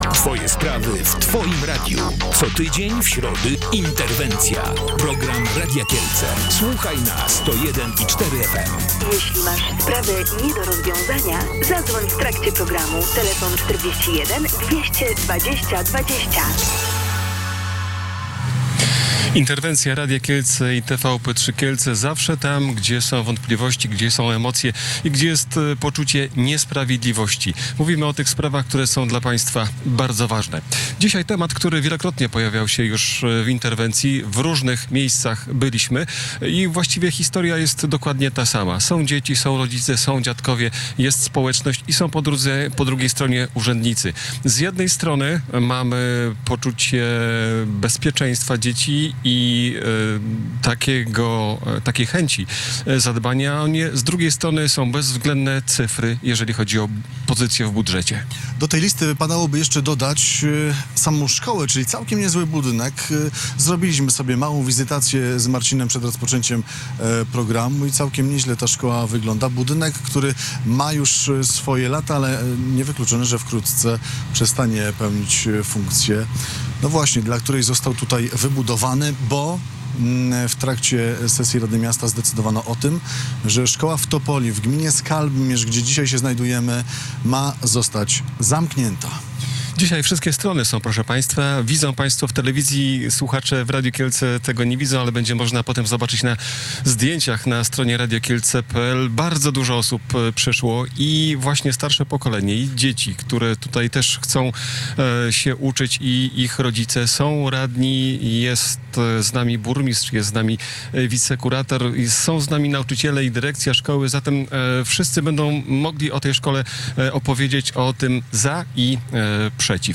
– Ja kupiłam tutaj dom 10 lat temu i odkąd mieszkam, zawsze były plotki o tym, że szkoła może zostać zamknięta – mówiła jedna z mam.